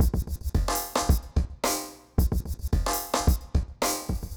RemixedDrums_110BPM_23.wav